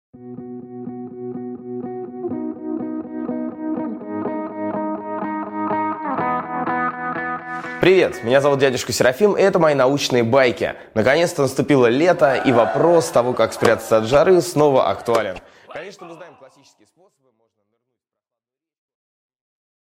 Аудиокнига Как охладиться в летний день | Библиотека аудиокниг
Прослушать и бесплатно скачать фрагмент аудиокниги